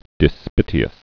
(dĭ-spĭtē-əs)